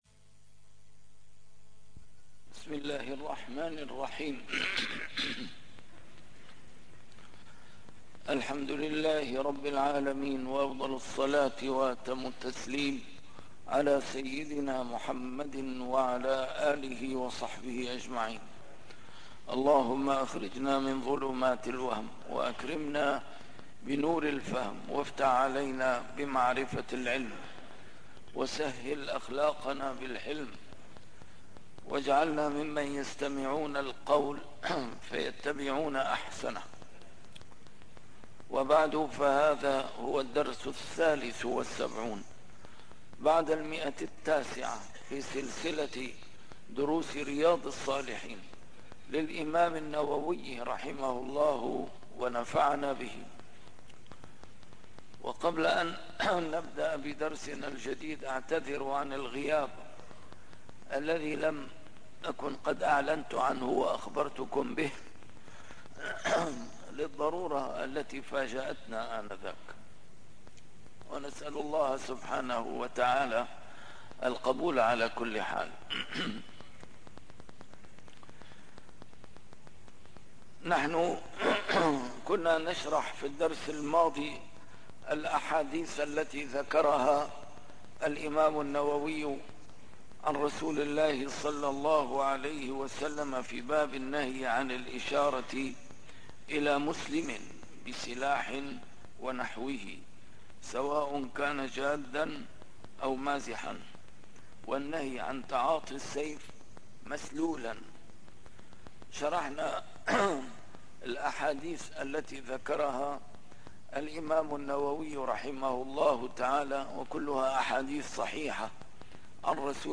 A MARTYR SCHOLAR: IMAM MUHAMMAD SAEED RAMADAN AL-BOUTI - الدروس العلمية - شرح كتاب رياض الصالحين - 973- شرح رياض الصالحين: النهي عن الإشارة إلى مسلم بسلاح ونحوه - كراهة الخروج من المسجد بعد الأذان